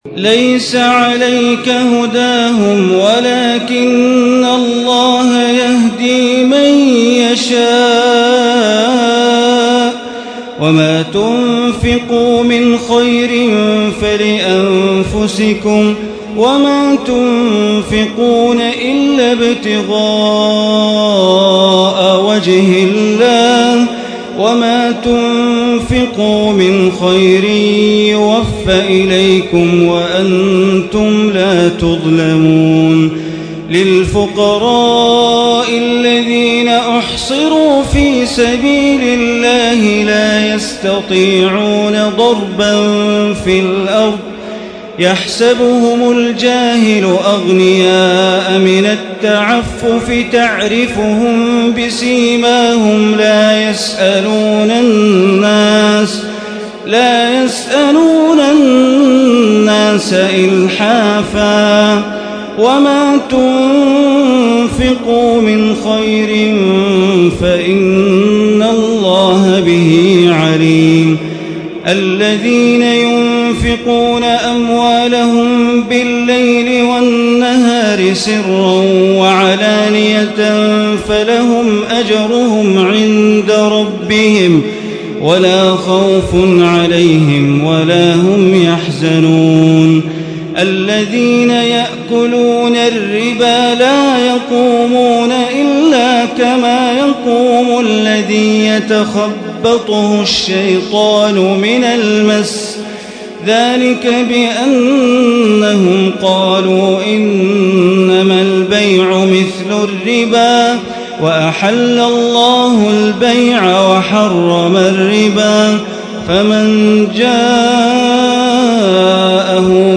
ليلة 03 من رمضان عام 1436 من سورة البقرة آية 272 إلى سورة آل عمران آية 63 > تراويح ١٤٣٦ هـ > التراويح - تلاوات بندر بليلة